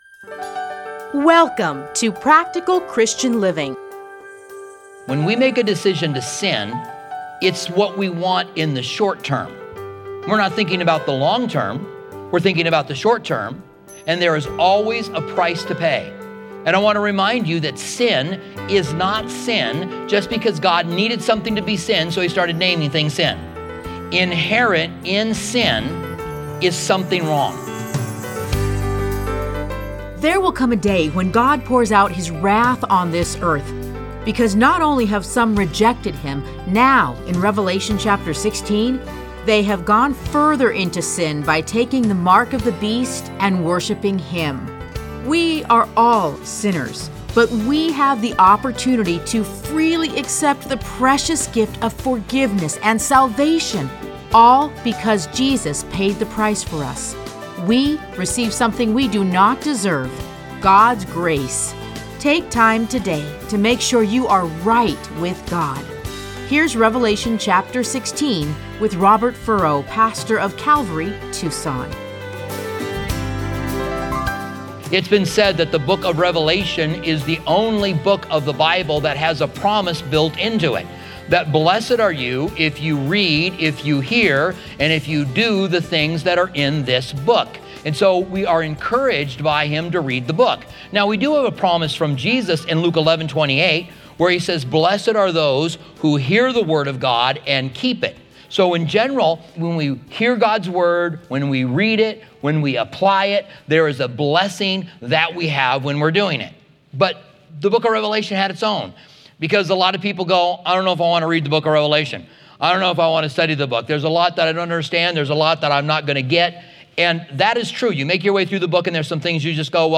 Listen to a teaching from Revelation 16:1-21.